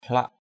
/hlaʔ/ (đg.) găm = mettre dans un étui, dans un fourreau. hlak dhaong h*K _d” găm dao = mettre le couteau dans le fourreau.